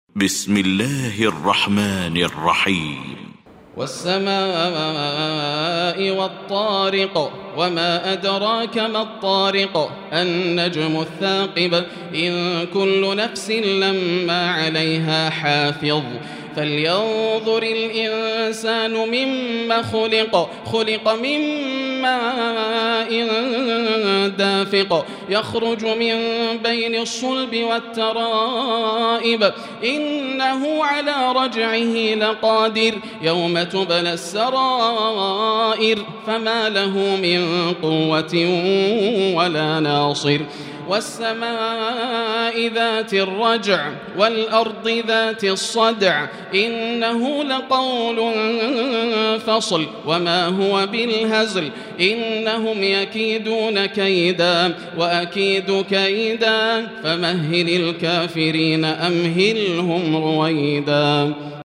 المكان: المسجد الحرام الشيخ: فضيلة الشيخ ياسر الدوسري فضيلة الشيخ ياسر الدوسري الطارق